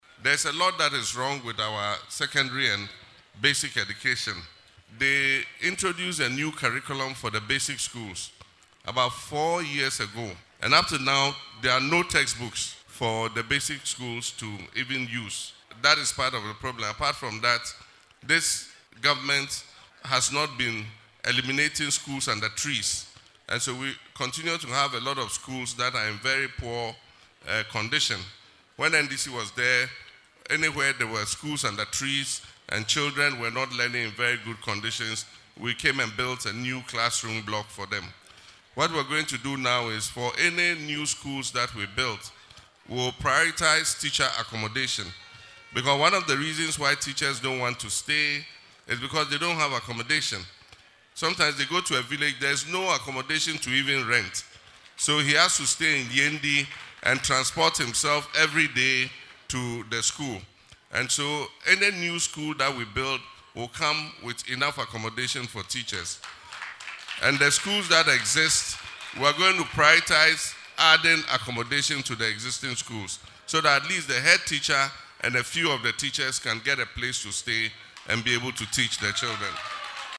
Speaking at a Town Hall meeting in Yendi, Mr. Mahama stated that many educational institutions lack textbooks, but the school authorities are afraid to speak out about these problems.